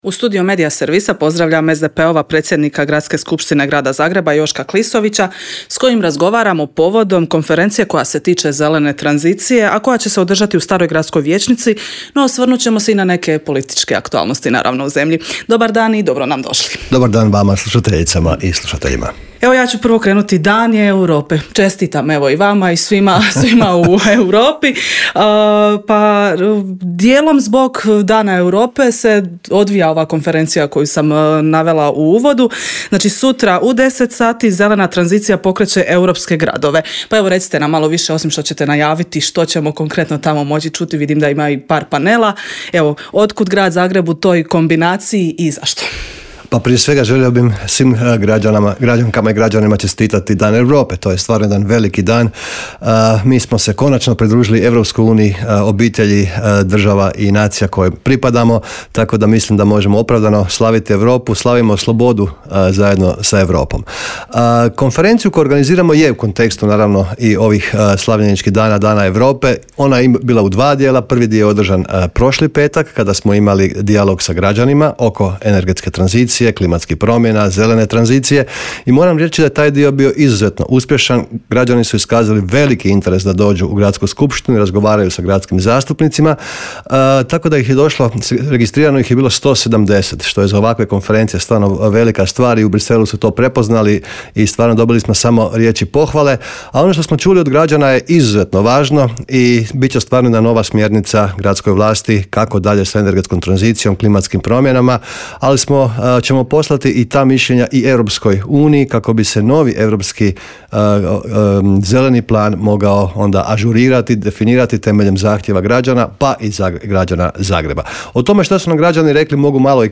ZAGREB - Povodom međunarodne Konferencije Zelena tranzicija pokreće europske gradove u Intervjuu Media servisa ugostili smo predsjednika Gradske skupš...